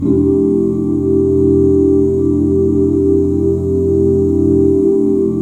FMAJ7 OOO -L.wav